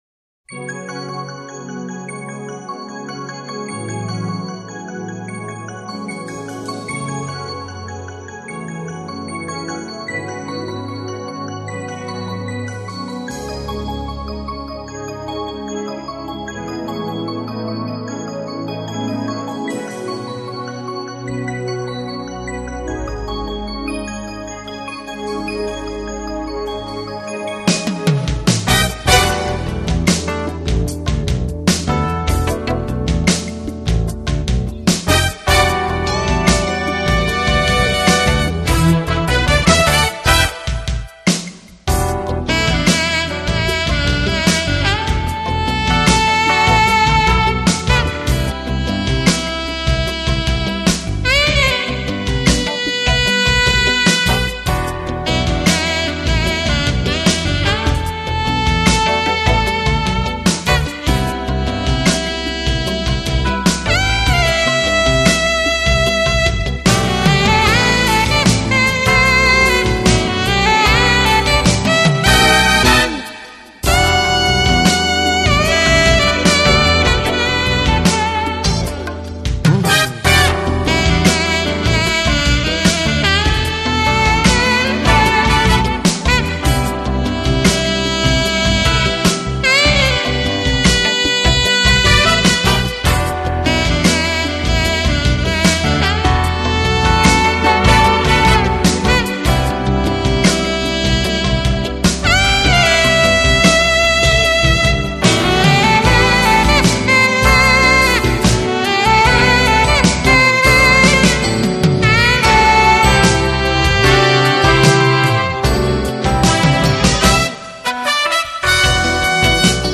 轻柔爵士钢琴